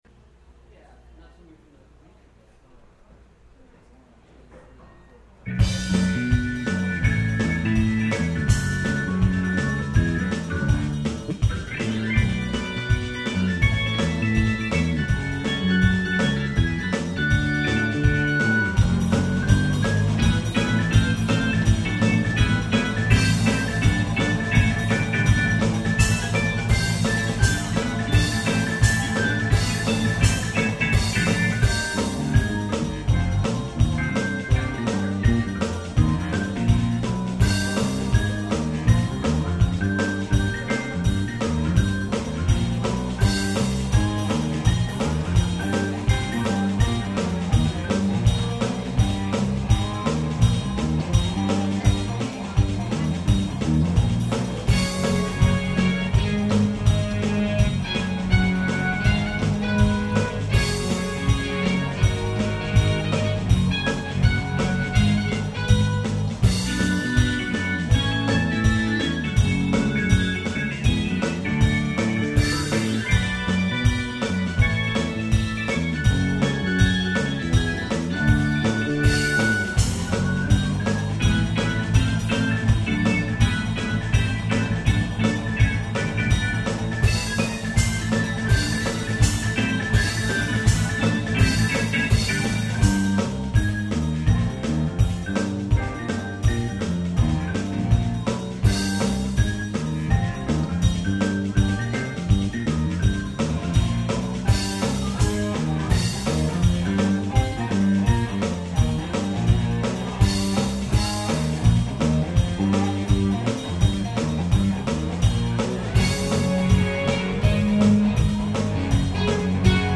"Live" at the Metaphor Cafe in Escondido, CA
keyboards, guitar
Bass
Electronic Drums